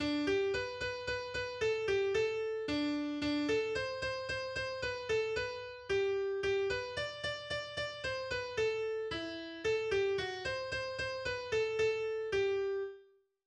Wiener Scherzlied